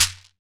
PRC SHEKER03.wav